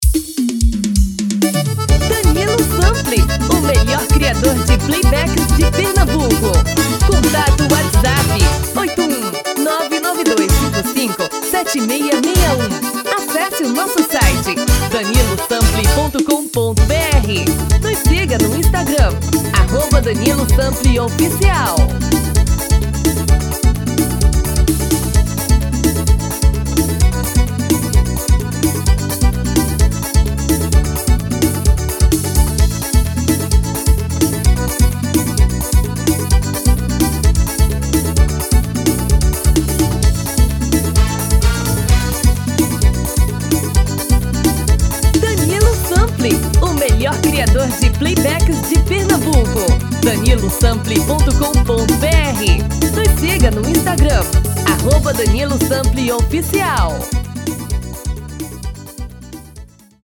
DEMO 1: tom original / DEMO 2: um tom abaixo